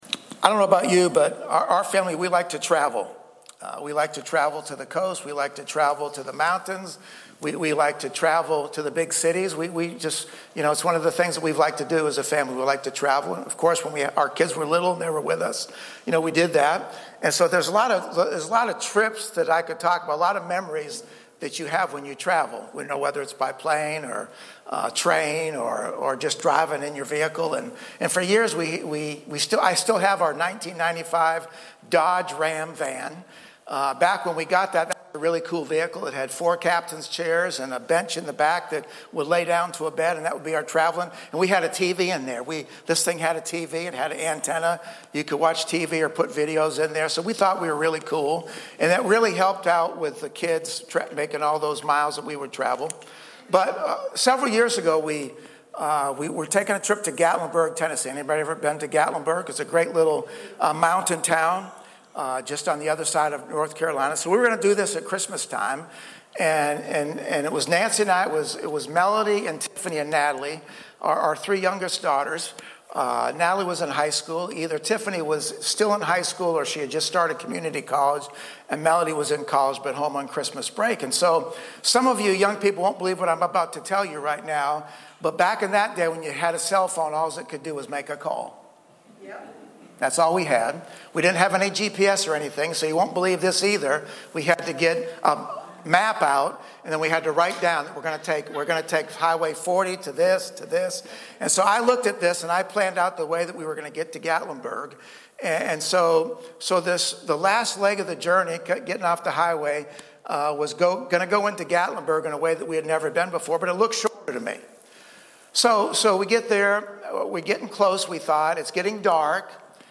2026 Roads of Passion Acts conversion Damascus Evangelism Sunday Morning In Acts 9:1-22